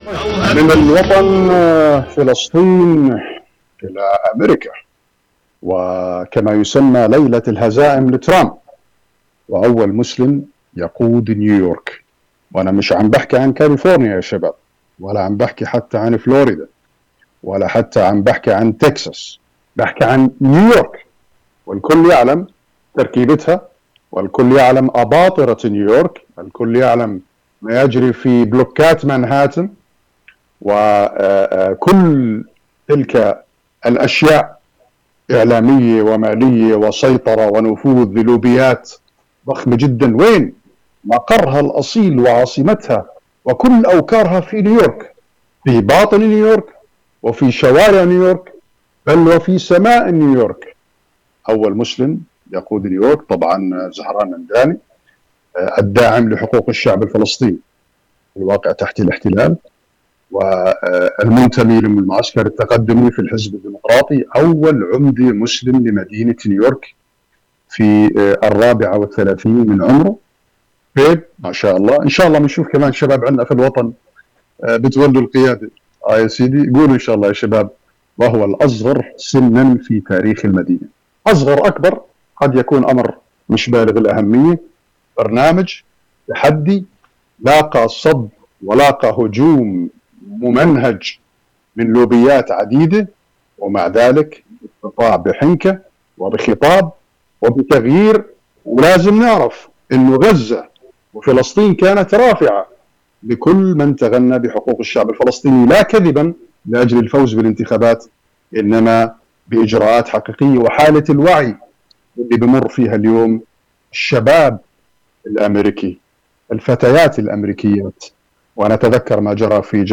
الصحفي الفلسطيني